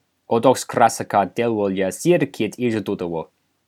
OdokcracaNativeNamePronounce.ogg